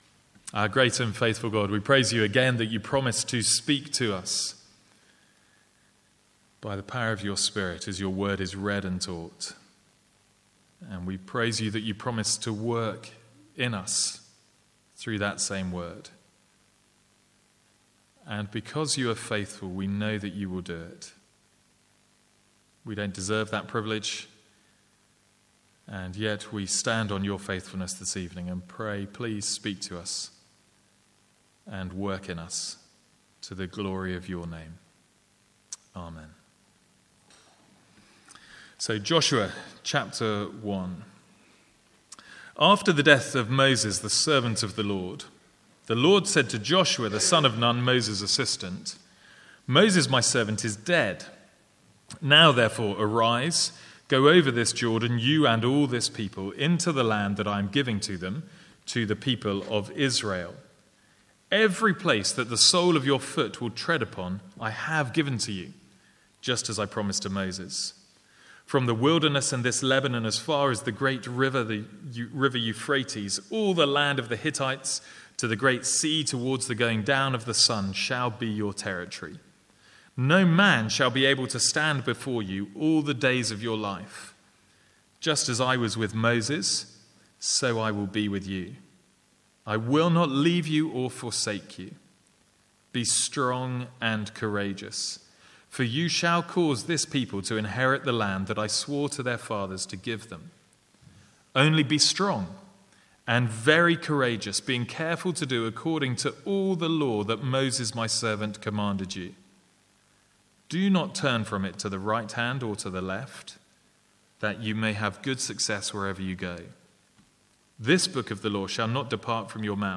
Sermons | St Andrews Free Church
From the Sunday evening series in Joshua.